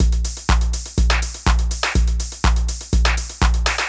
ENE Beat - Mix 3.wav